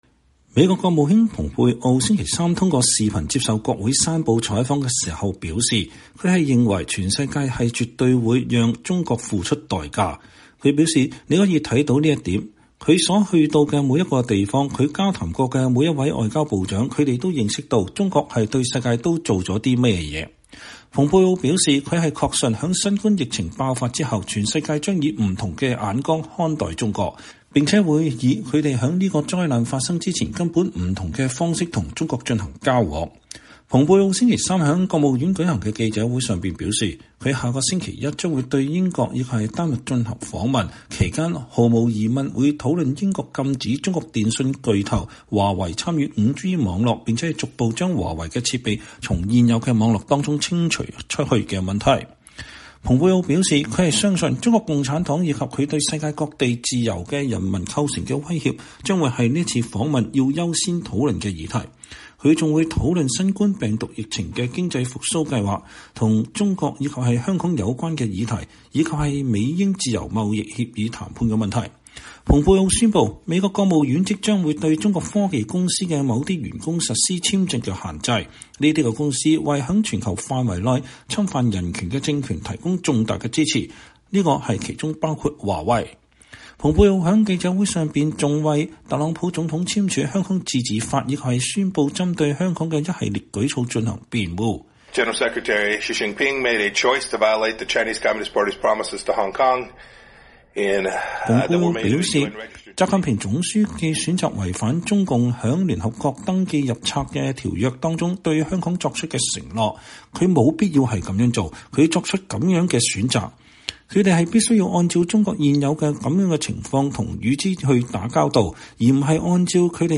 美國國務卿蓬佩奧在國務院舉行的記者會上講話。(2020年7月15日)